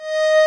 D#5 ACCORD-L.wav